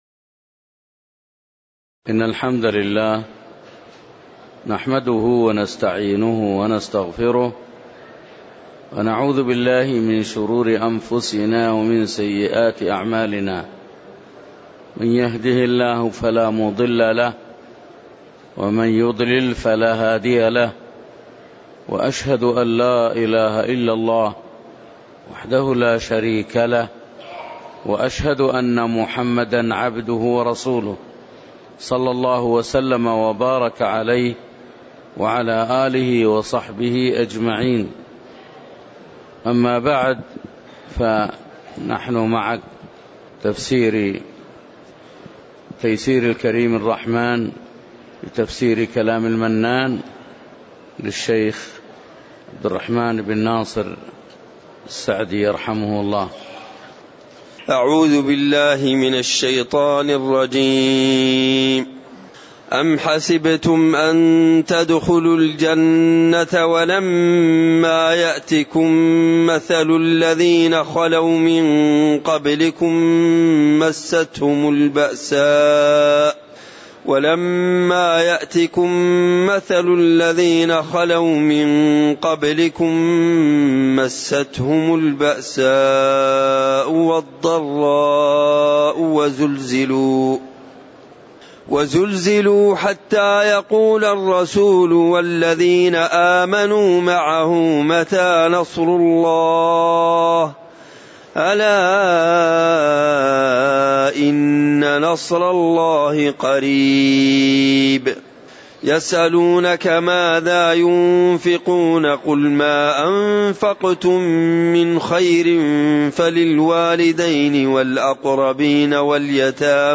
تاريخ النشر ٩ محرم ١٤٣٩ هـ المكان: المسجد النبوي الشيخ